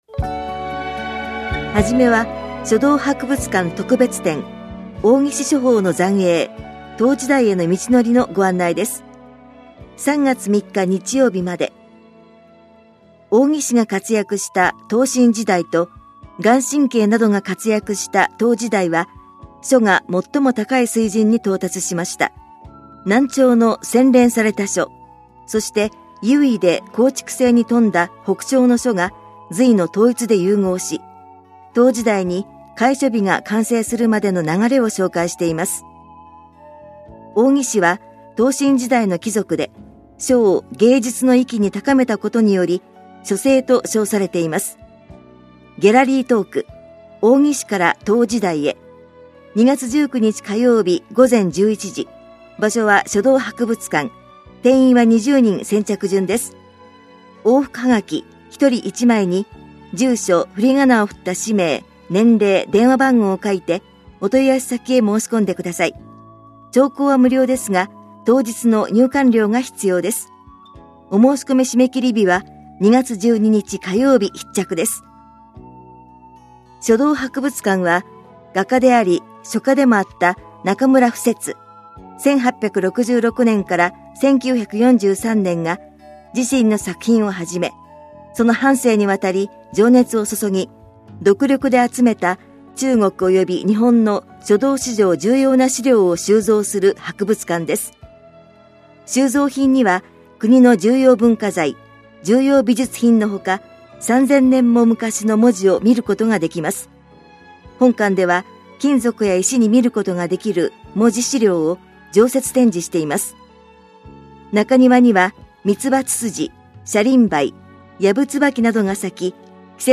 広報「たいとう」平成31年1月20日号の音声読み上げデータです。